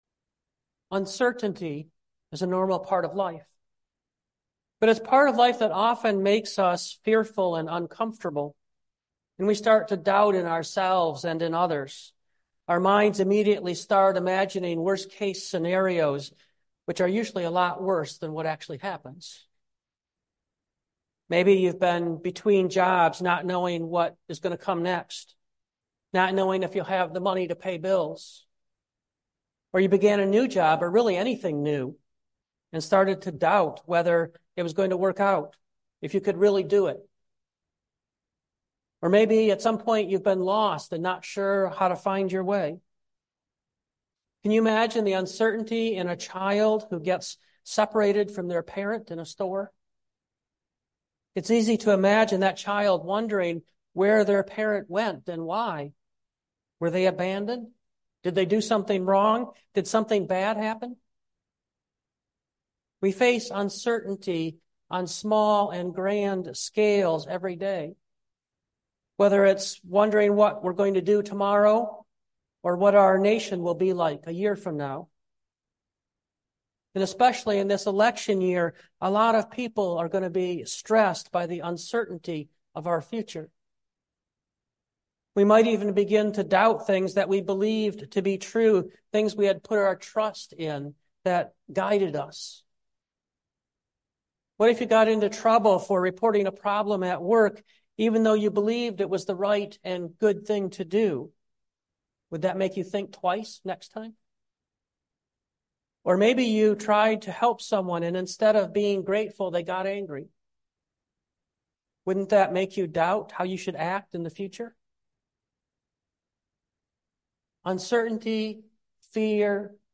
2024 Facing Uncertainty Preacher